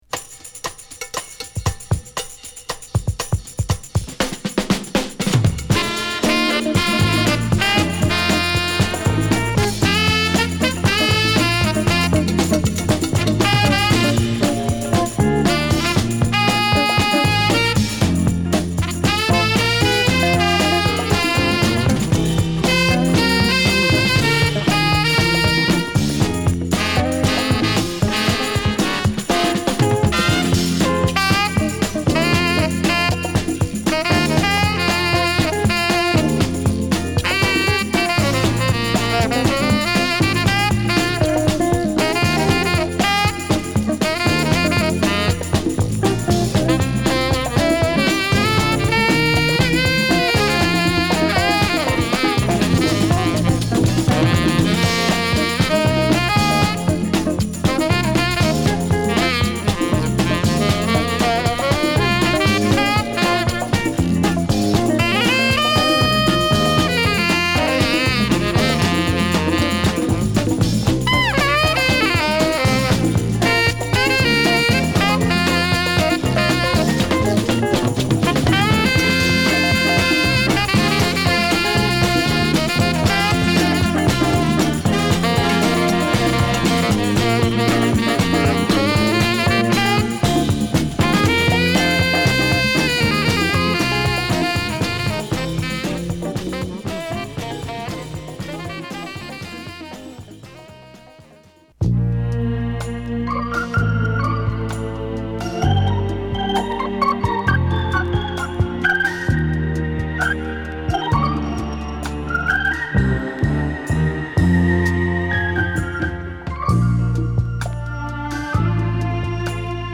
疾走感あるジャズファンク